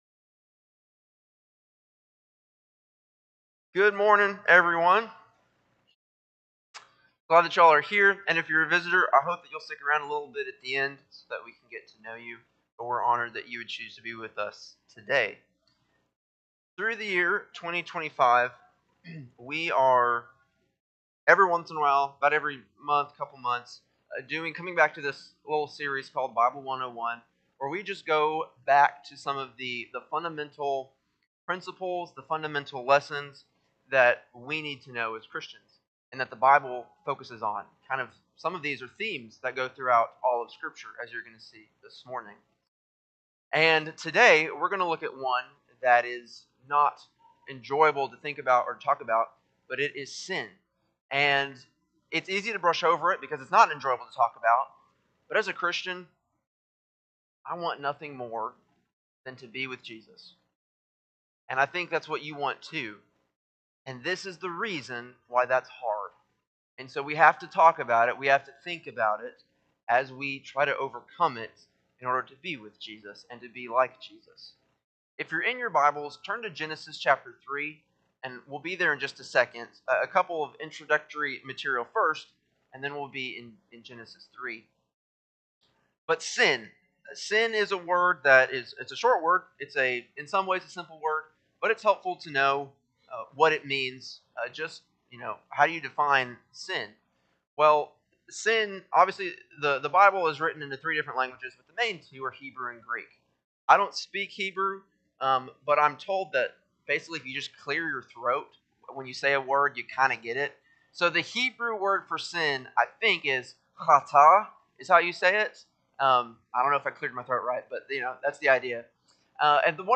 Sunday AM Sermon
Sunday-AM-Sermon-7-20-25.mp3